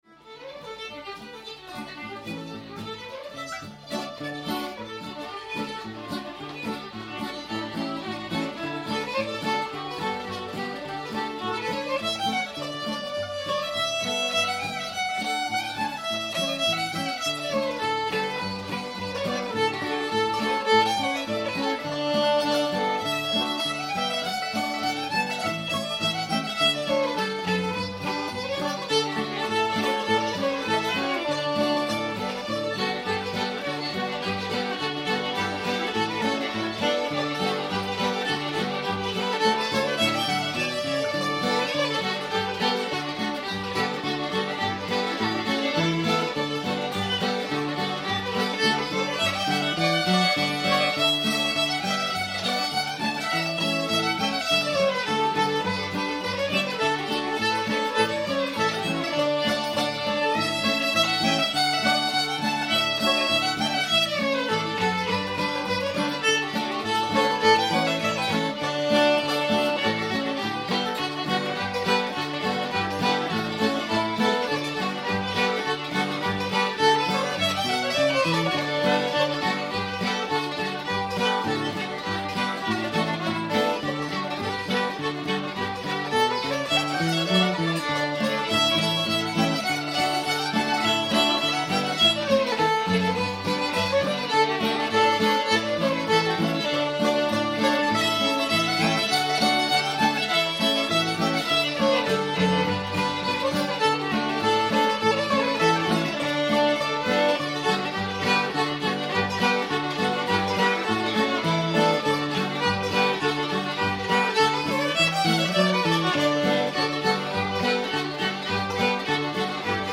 fisher's hornpipe [D]